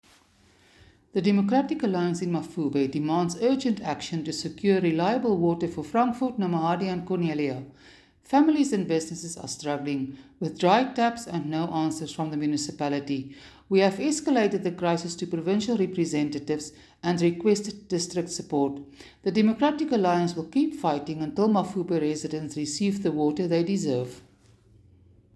Afrikaans soundbites by Cllr Suzette Steyn and